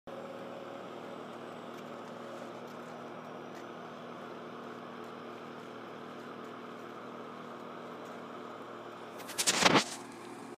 Poi d’improvviso la cava “esplosiva” soprastante riprende l’attività, macchine perforatrici e colpi di mina si alternano con regolarità, l’atmosfera si rabbuia e la parte visibile non basta più a rallegrarci, piombiamo nel rumore, l’incanto è finito, l’Engadina torna ad essere lontana e irraggiungibile!